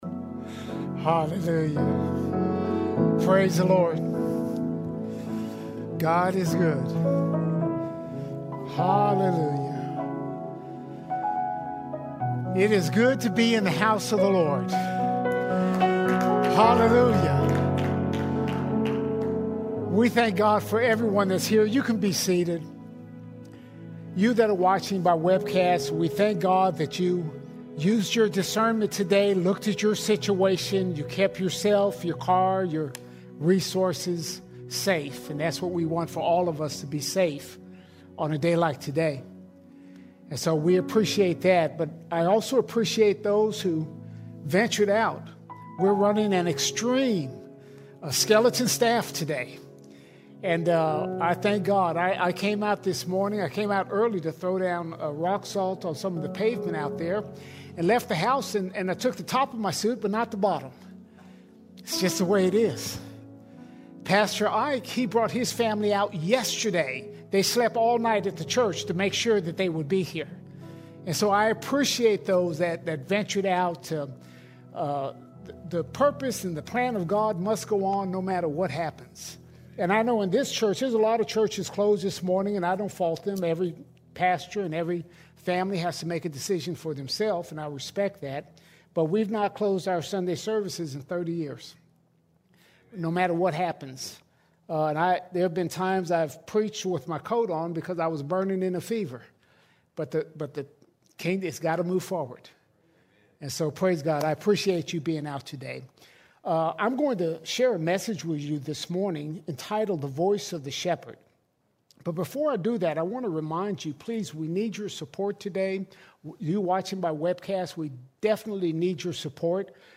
27 January 2026 Series: Sunday Sermons All Sermons Following Behind the Shepherd Following Behind the Shepherd We’ve been called to live God-centered lives by following behind the Shepherd.